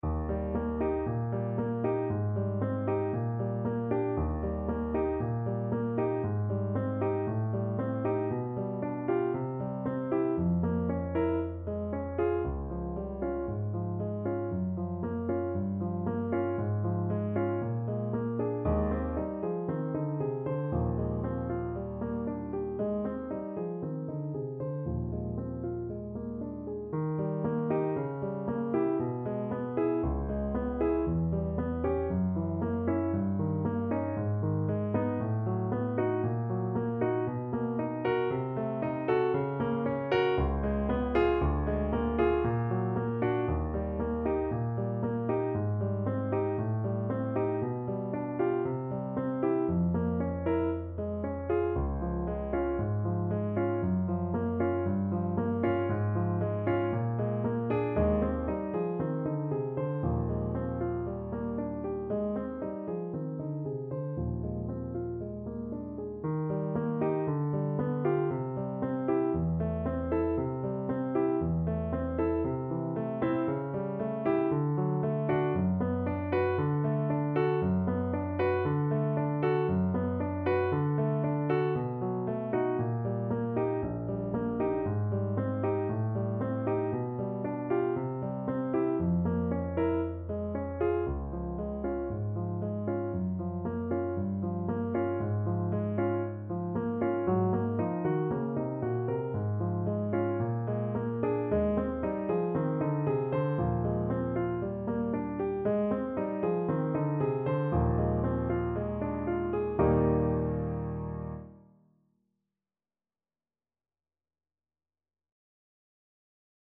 Allegro moderato =116 (View more music marked Allegro)
Classical (View more Classical Saxophone Music)